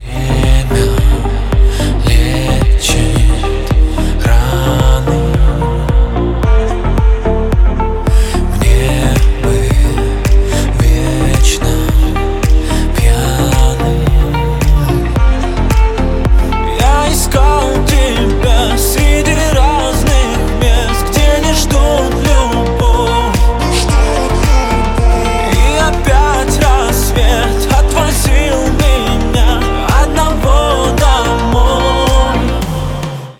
• Качество: 128, Stereo
поп
мужской вокал
грустные